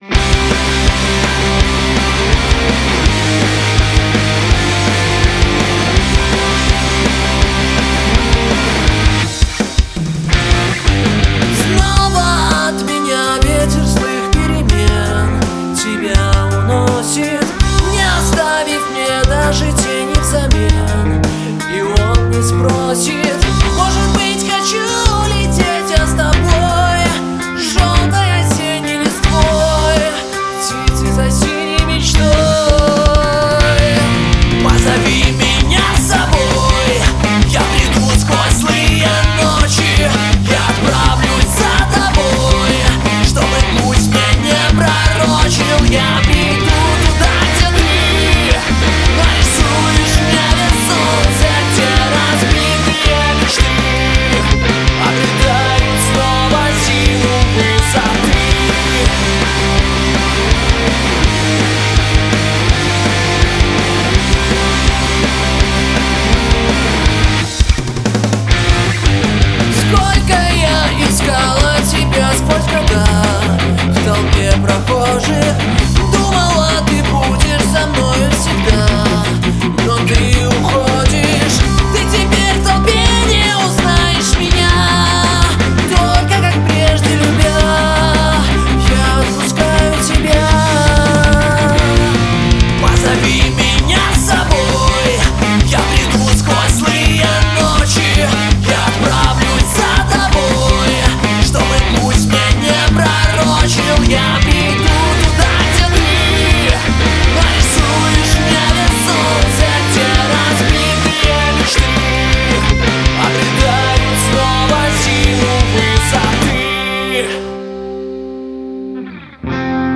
кавер